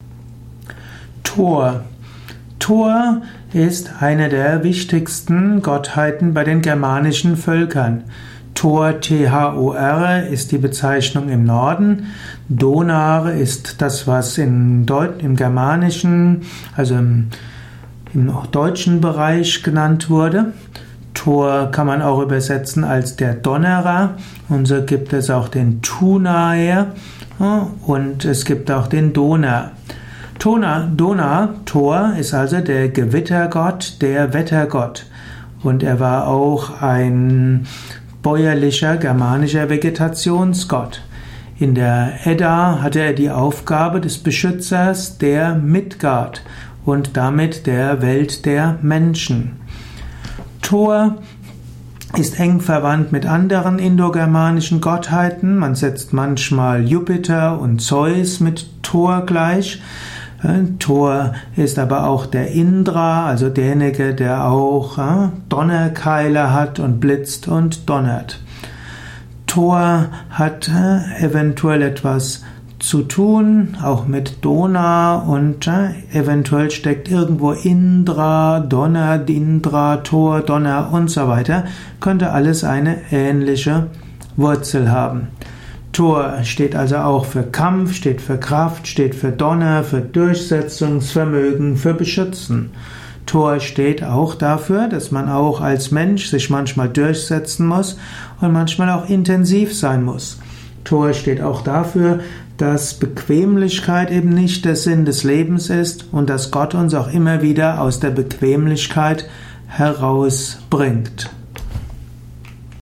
Ein Audio Vortrag über Thor, einem germanischen Gott. Eruierung der Bedeutung von Thor in der germanischen Mythologie, im germanischen Götterhimmel.
Dies ist die Tonspur eines Videos, zu finden im Yoga Wiki.